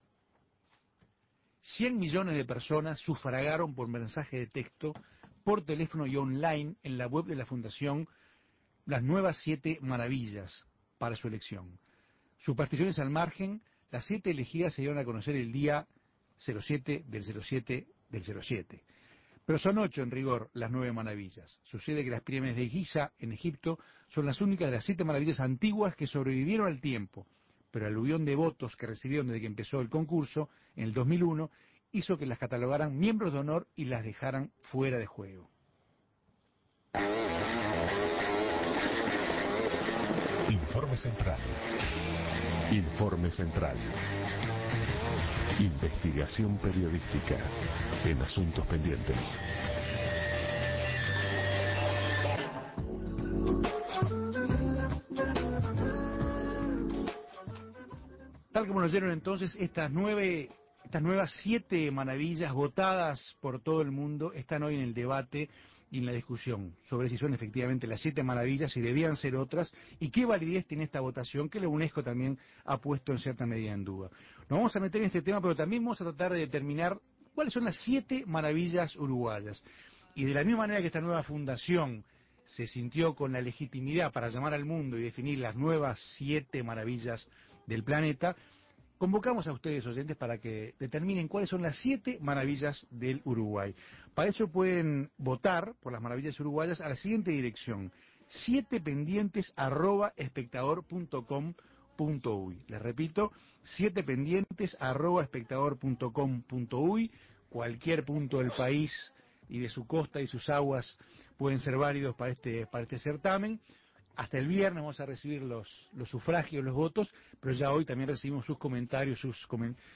Más de 100 millones de personas votaron y elegieron ocho maravillas. Surge el debate acerca de la legitimidad de la votación, la UNESCO está en desacuerdo con el resultado. Escuche además el testimonio de uruguayos que conocieron algunas de estas maravillas.
Informes